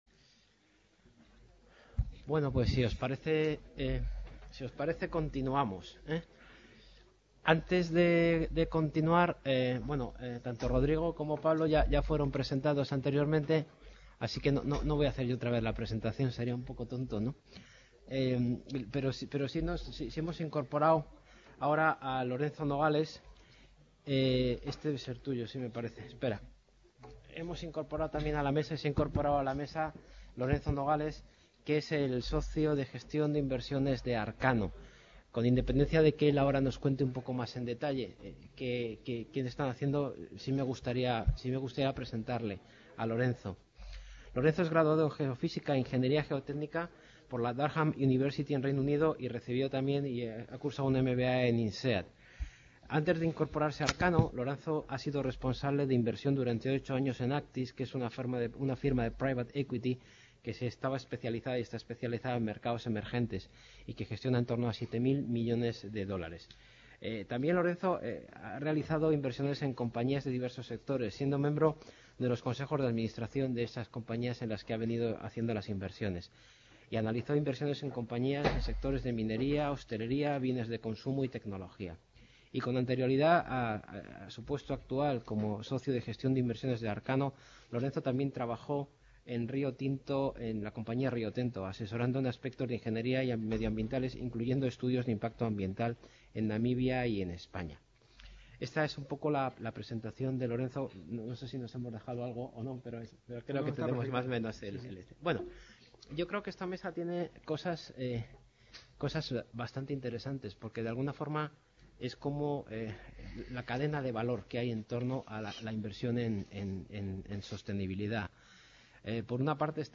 MESA REDONDA
Reunion, debate, coloquio...